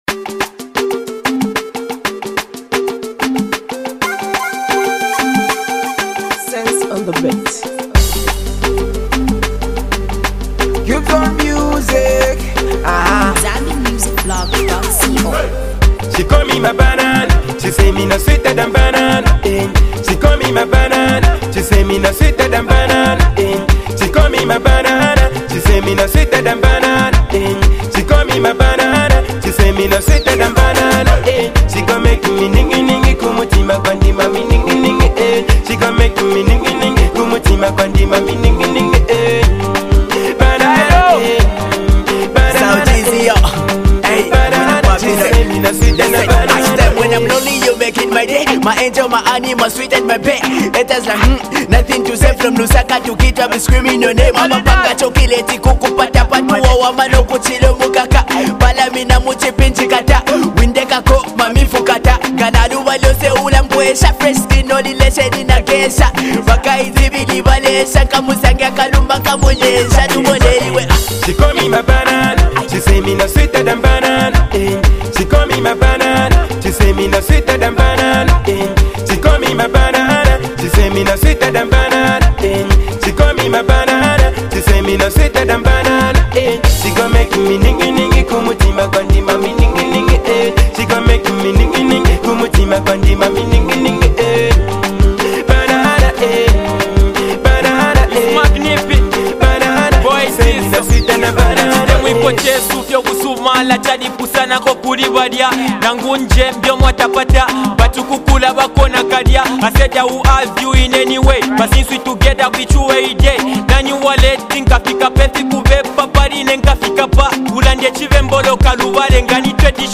An uprising Zambian music group
energetic singles